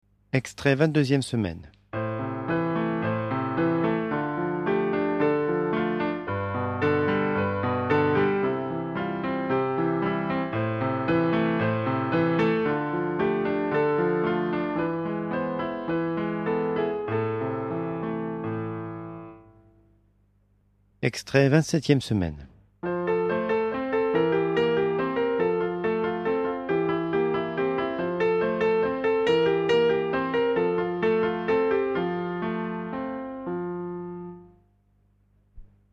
Genre : Disco